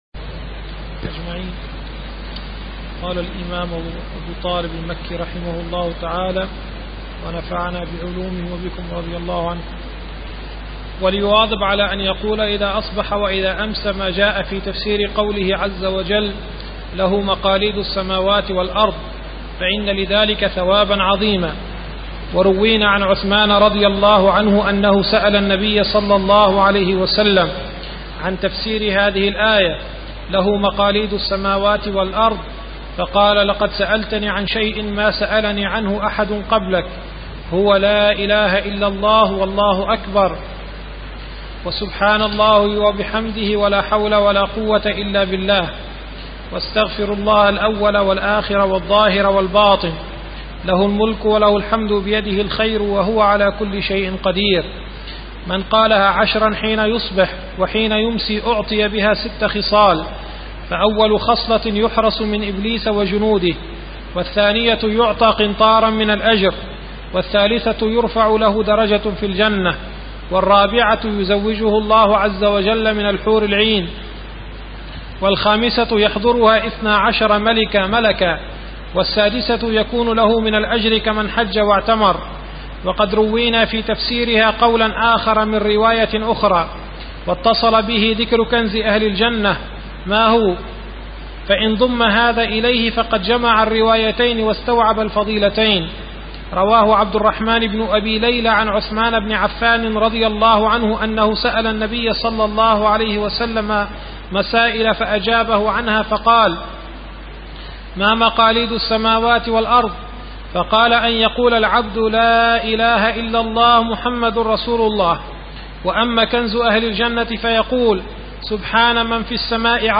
شرح كتاب قوت القلوب - الدرس العاشر